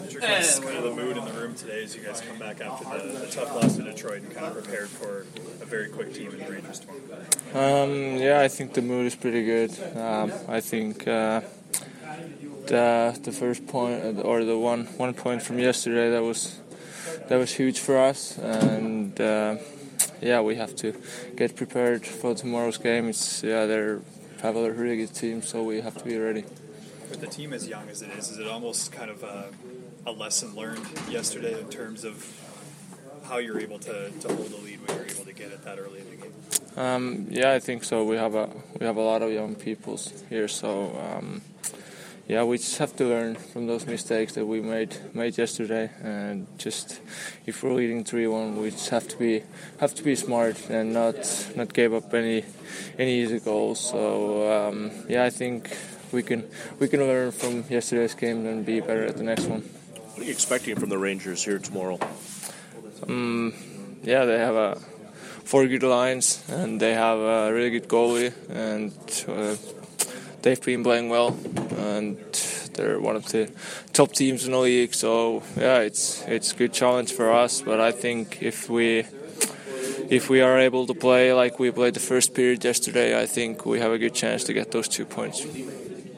December-7-2016-Patrik-Laine-scrum.mp3